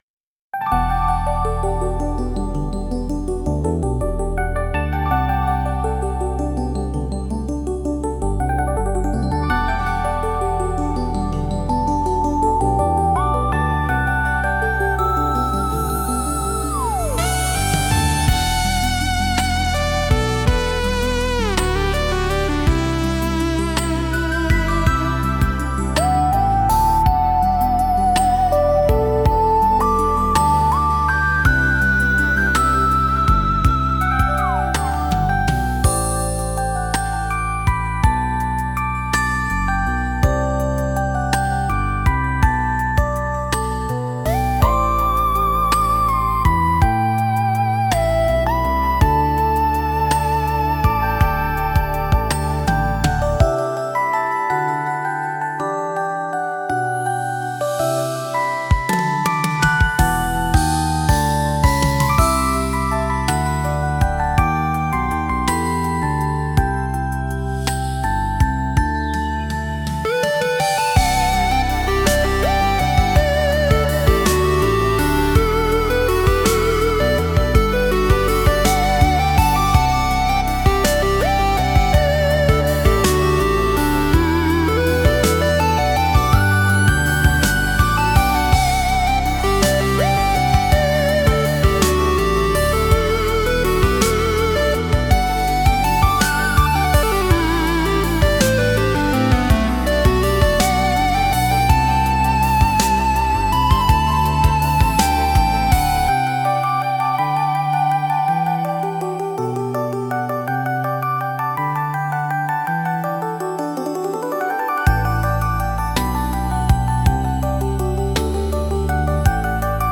素朴なギターの爪弾きが安心感を与え、空を舞う鳥のように自由なフルートのメロディが、未知の景色への好奇心をかき立てます。
• ジャンル： アコースティック / ワールド / フォーク / ヒーリング
• 雰囲気： 壮大 / 清潔感 / 穏やか / 郷愁 / 透明感
• テンポ（BPM）： ミドル（心地よい歩みの速さ）